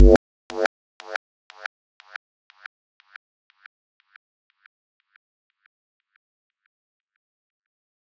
Rev Bass Delay.wav